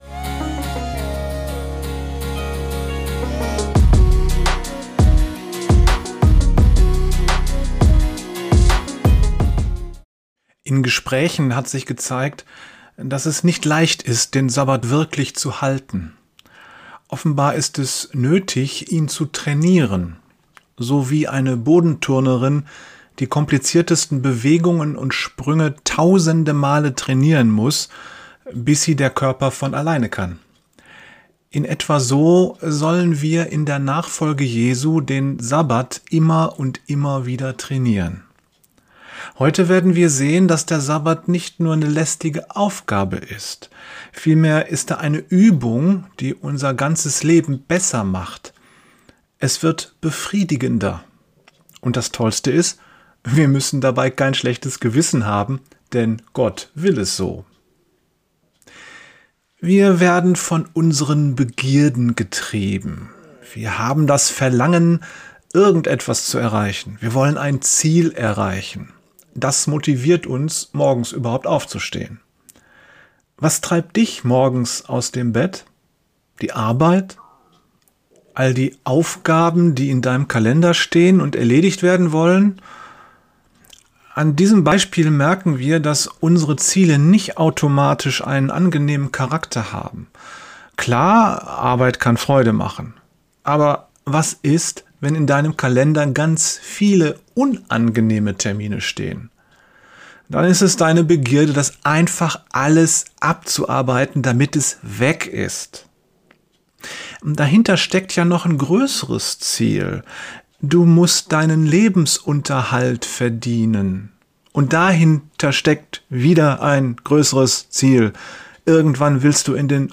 IMMER MIT DER RUHE! Den Sabbat halten ~ Geistliche Inputs, Andachten, Predigten Podcast